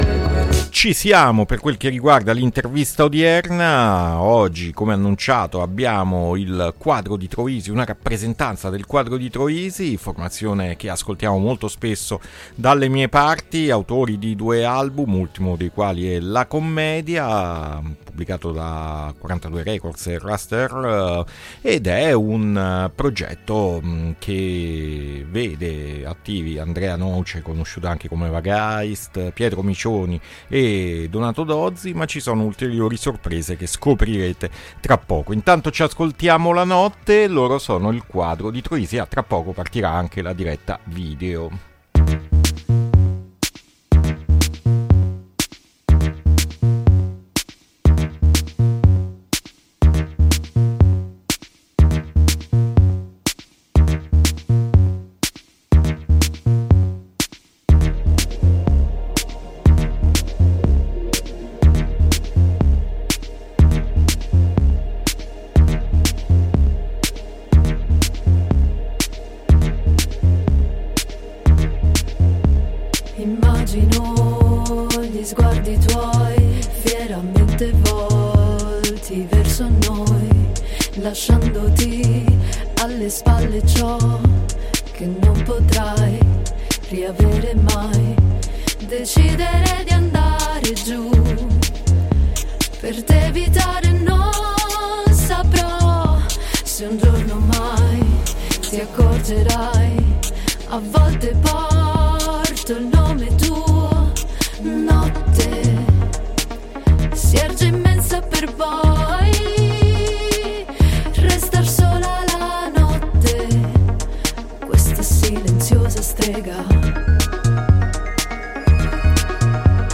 INTERVISTA IL QUADRO DI TROISI A PUZZLE 8-7-2024